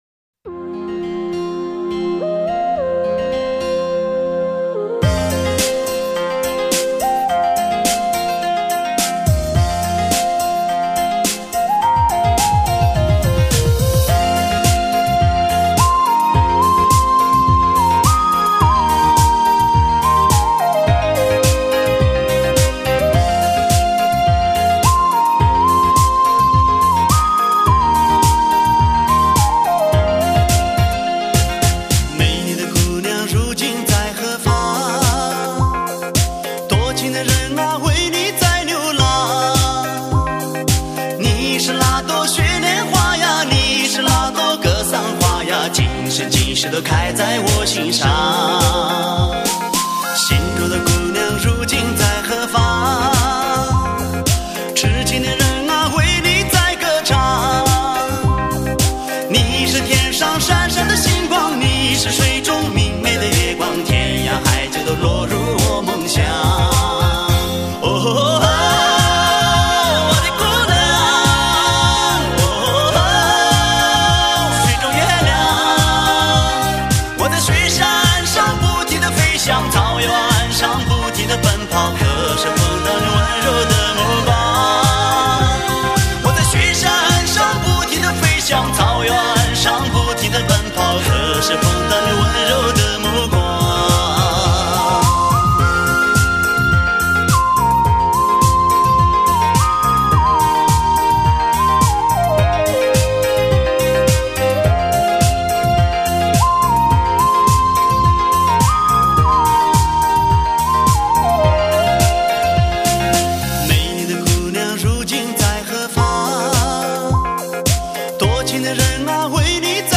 挑选最优秀的民族歌曲联手打造一部来自西藏高原的声音传奇。